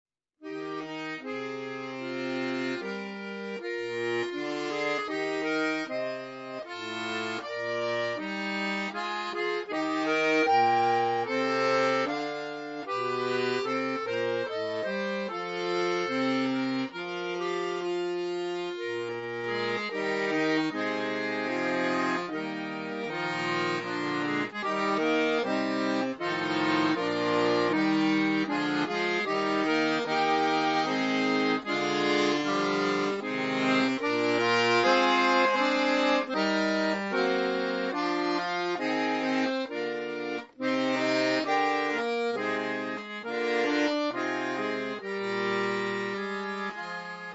Adagio, a majestic melody.